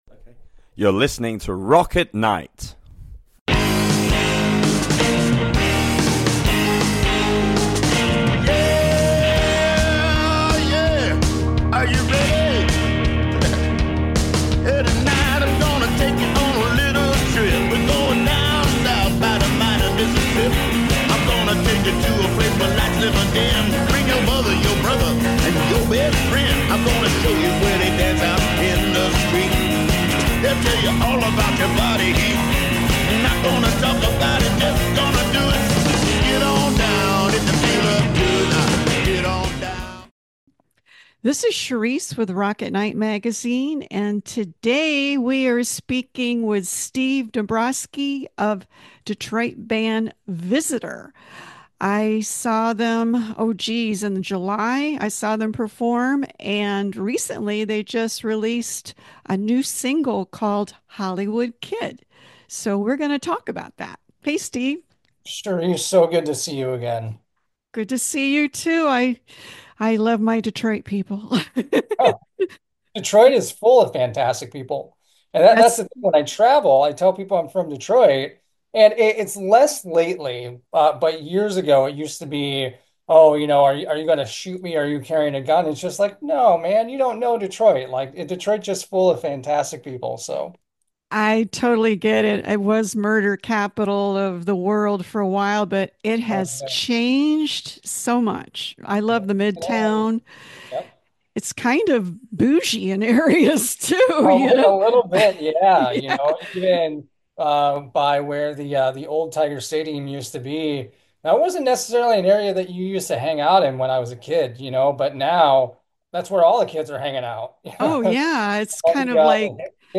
Chatting with Detroit band Visitor
Interview
chatting-with-detroit-band-visitor